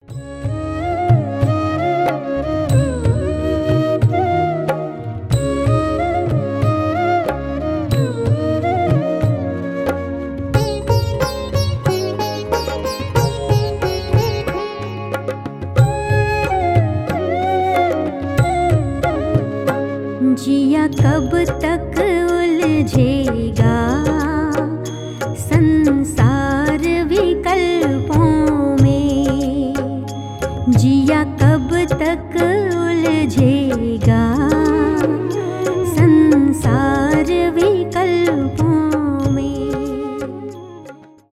индийские , мелодичные , спокойные , инструментальные